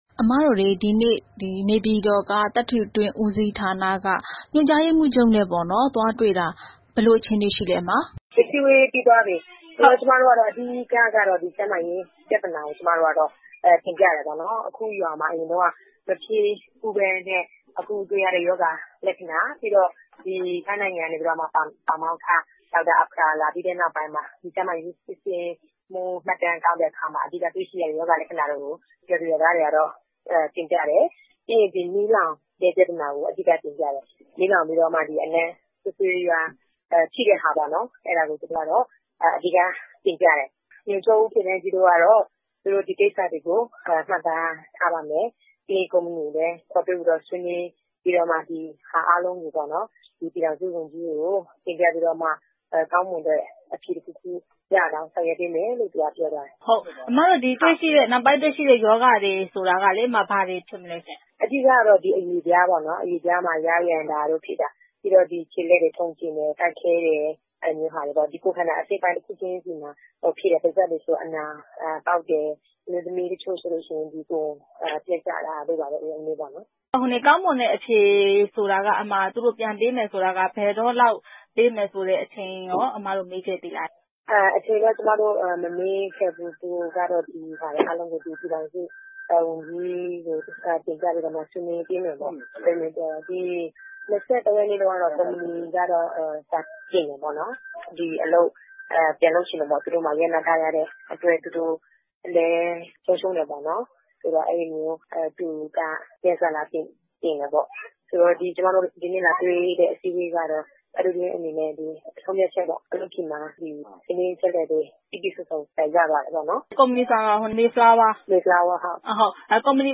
ဘန်းချောင်း ကျောက်မီးသွေးစီမံကိန်း ရပ်ဆိုင်းဖို့ တောင်းဆိုမှုအကြောင်း မေးမြန်းချက်